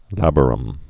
(lăbər-əm)